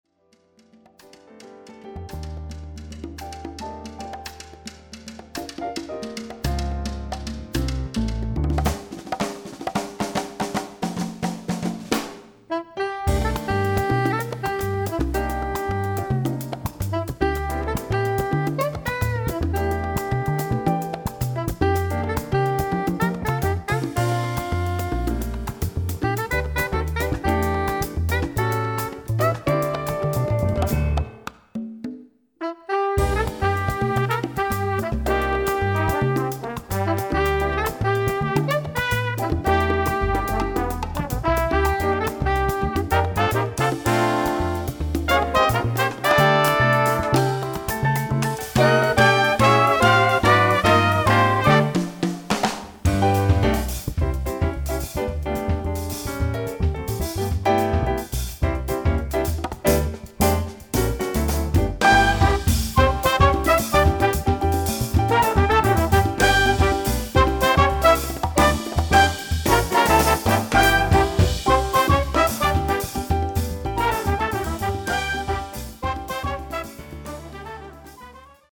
Category: combo (octet)
Style: mambo